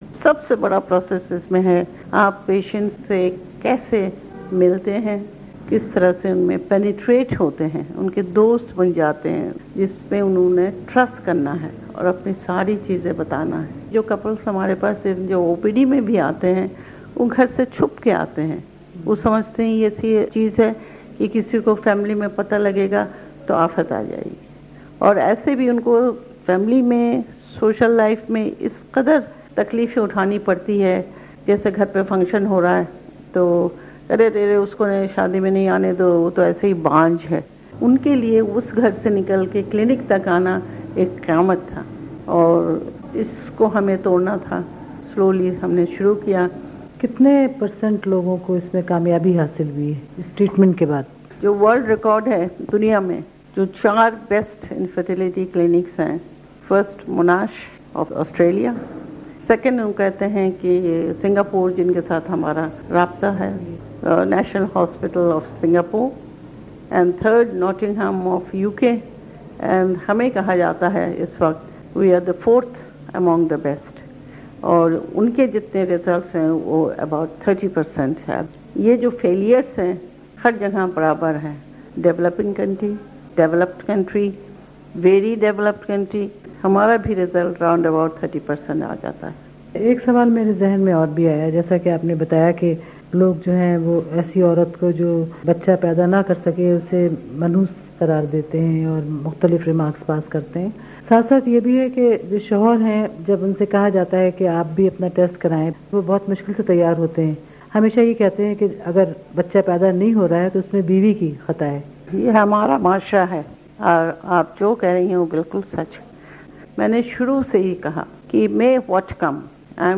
خصوصی انٹرویو